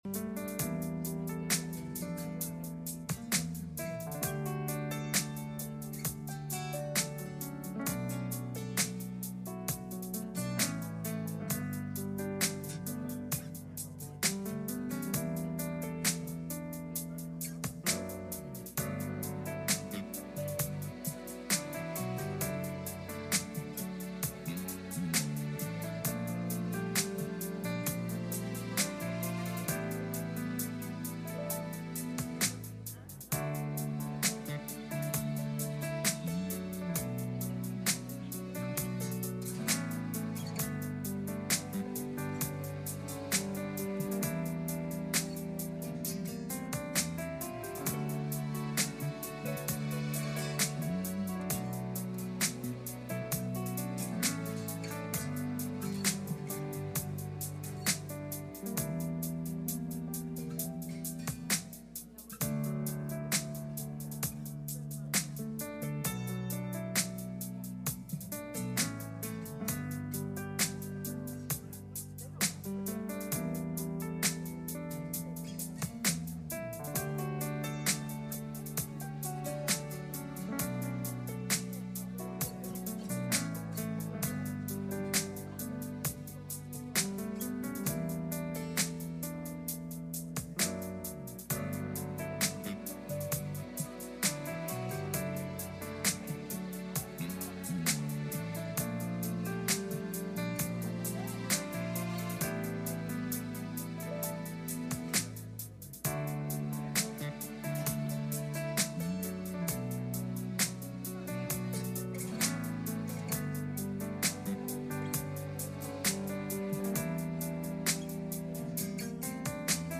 Message Passage: Colossians 1:1-12 Service Type: Midweek Meeting « Don’t Forget Where You Came From